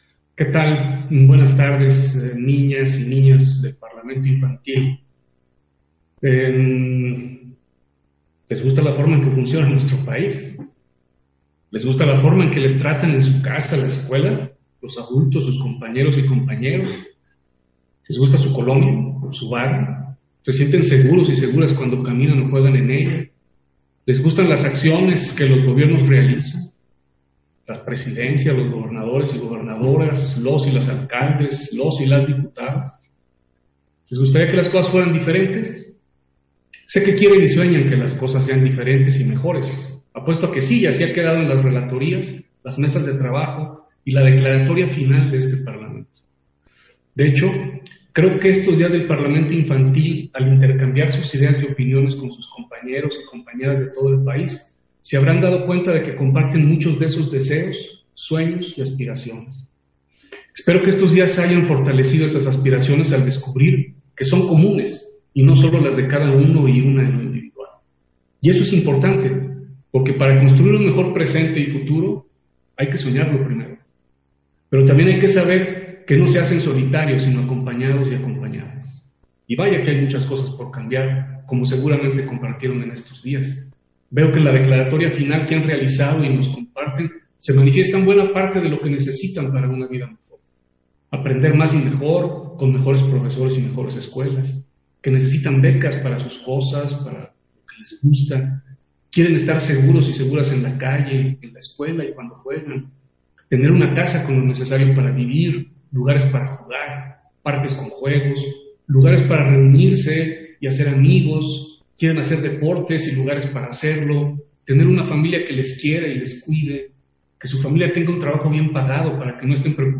Intervención de Martín Faz, en la clausura del 11º Parlamento de las Niñas y los Niños de México 2020